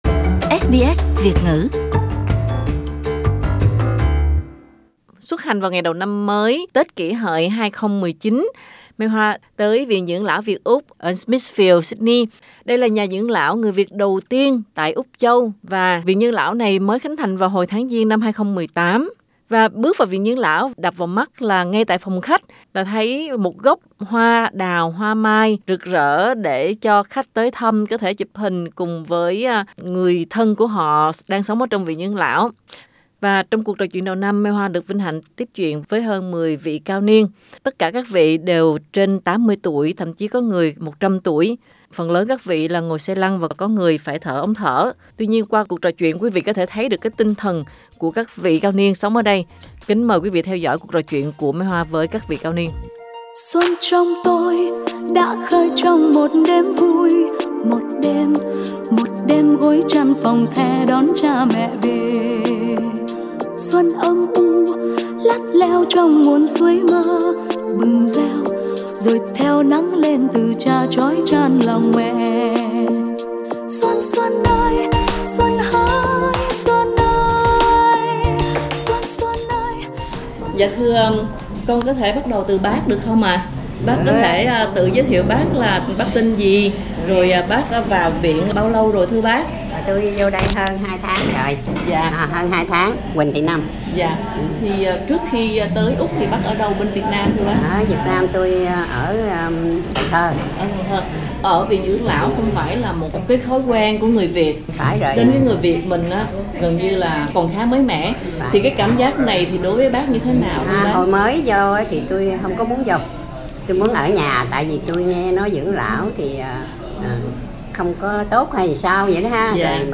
Mùng 1 Tết Kỷ Hợi 2019 tại Viện Dưỡng lão Việt Úc-Sydney Source: SBS